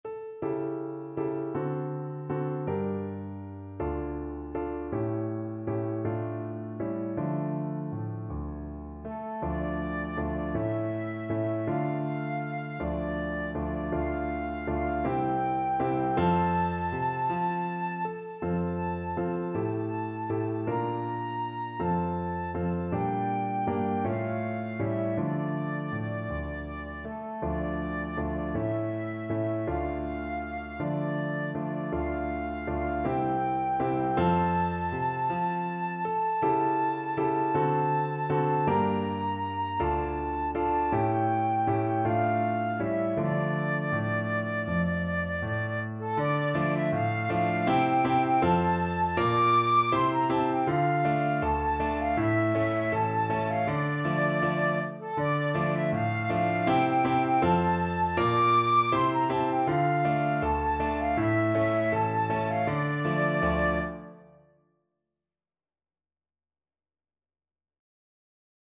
6/8 (View more 6/8 Music)
Andante